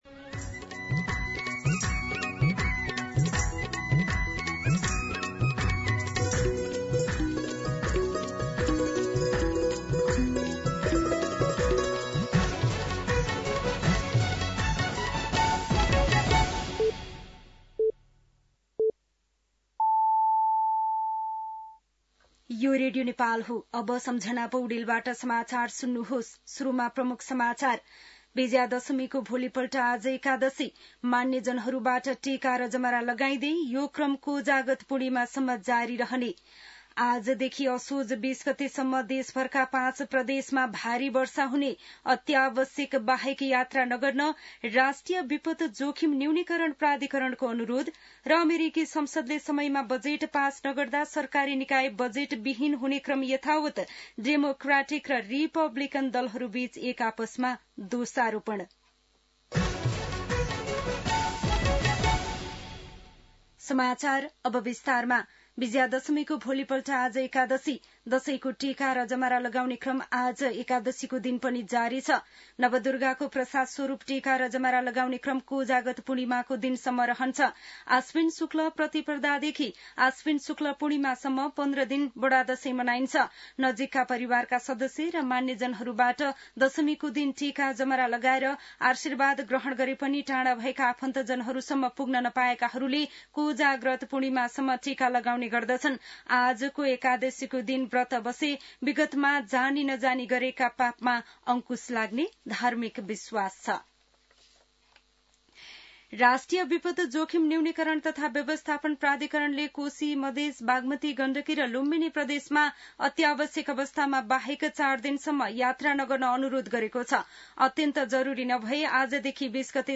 An online outlet of Nepal's national radio broadcaster
दिउँसो ३ बजेको नेपाली समाचार : १७ असोज , २०८२
3pm-News.mp3